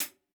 TC Live HiHat 14.wav